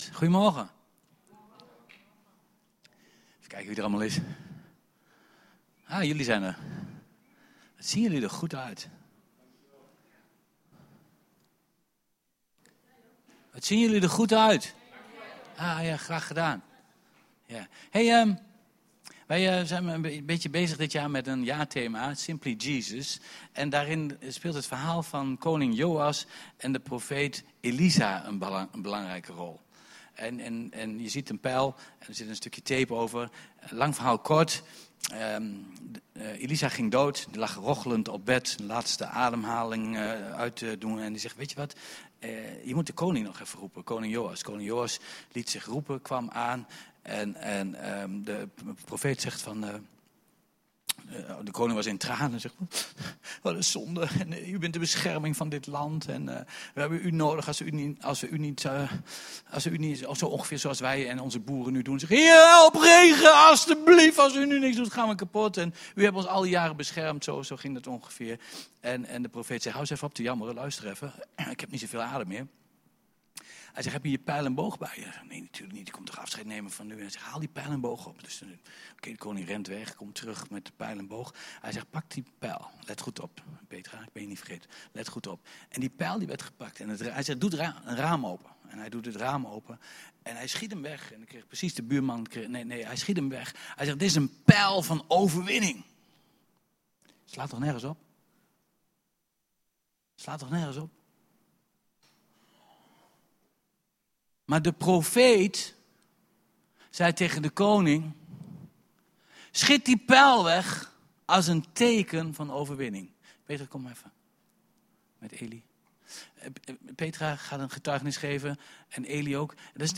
Luister ook hier het mooie voorbeeld terug in de vorm van een getuigenis wat gegeven wordt.